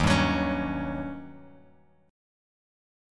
D#7#9 chord